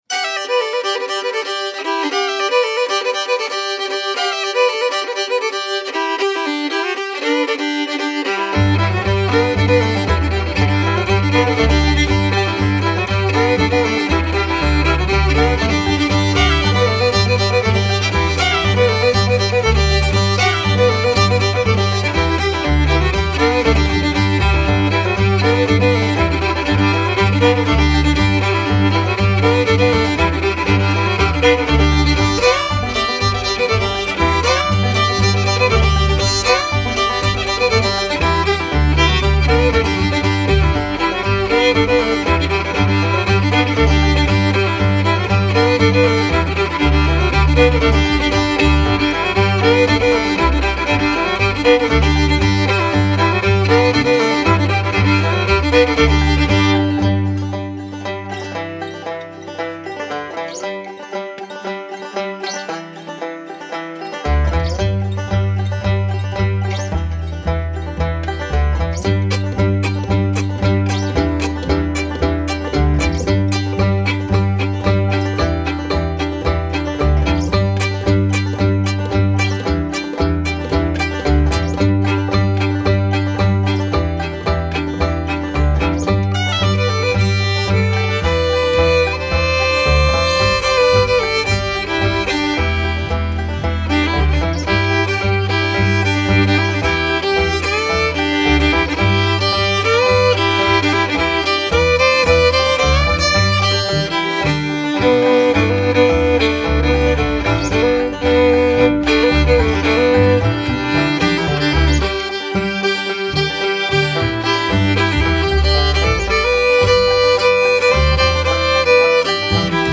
is a traditional tune
bass